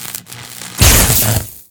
stungun.wav